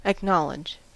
Ääntäminen
Vaihtoehtoiset kirjoitusmuodot (vanhentunut) knowleche (vanhentunut) acknowledg Synonyymit admit allow confess avow concede recognize proclaim own Ääntäminen US RP : IPA : /əkˈnɒlɪdʒ/ US : IPA : /ækˈnɑl.ɪdʒ/